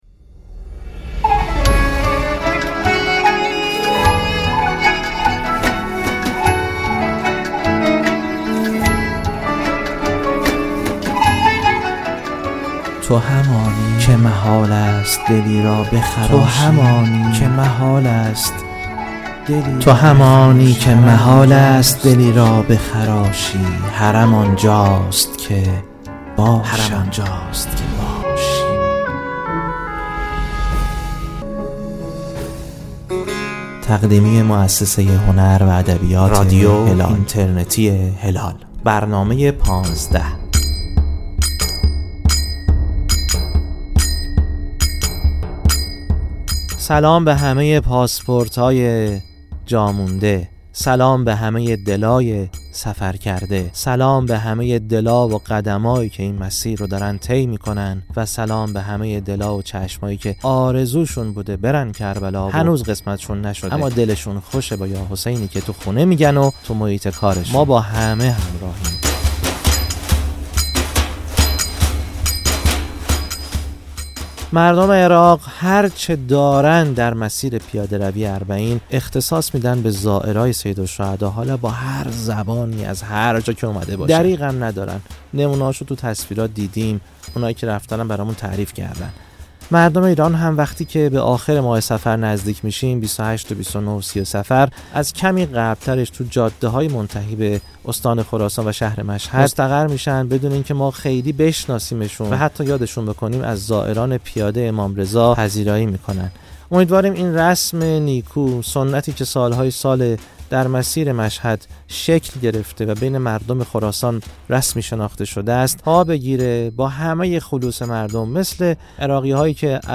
کم کم صدای گام‌هایی را می‌شنوید که خاک را جا می‌گذارند و به آسمان نزدیک‌تر می‌شوند.